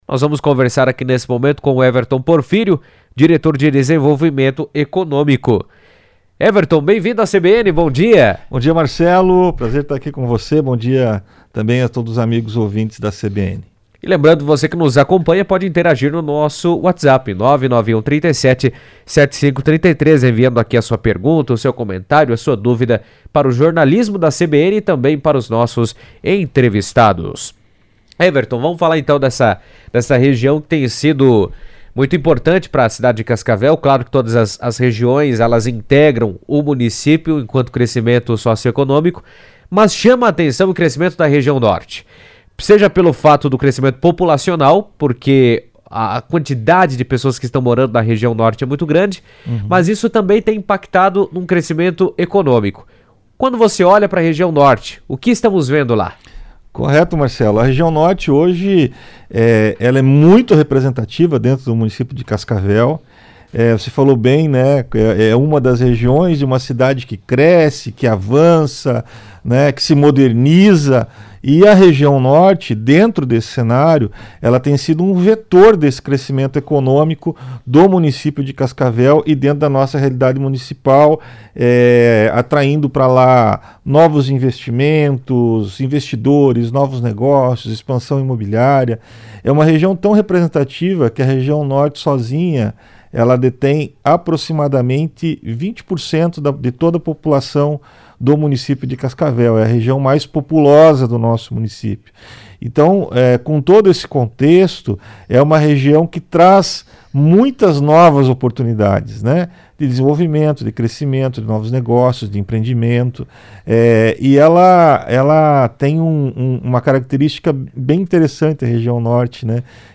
Em entrevista à CBN